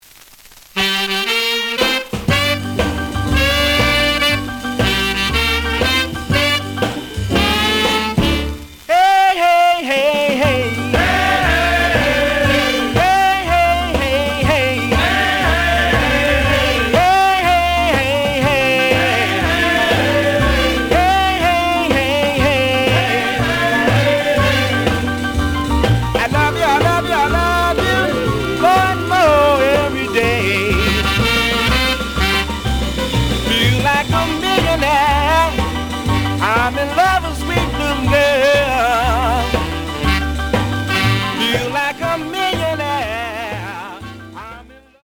The audio sample is recorded from the actual item.
●Genre: Rhythm And Blues / Rock 'n' Roll
Slight affect sound.